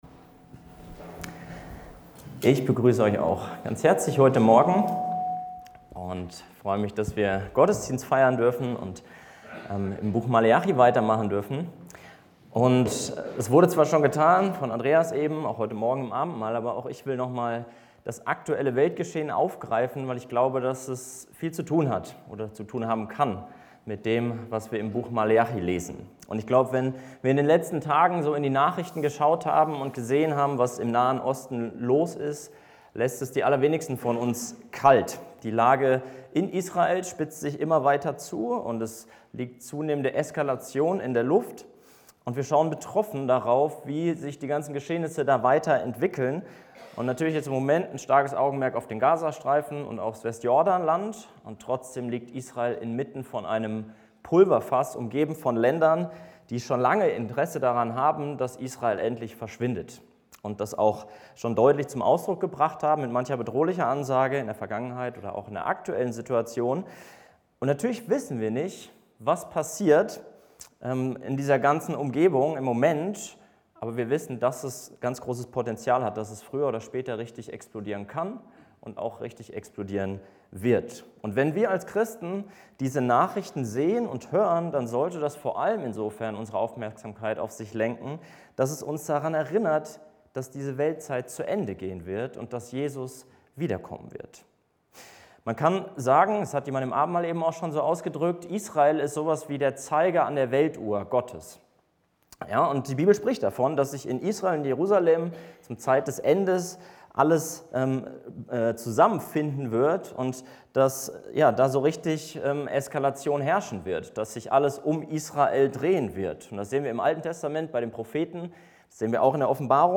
Thematik Lehre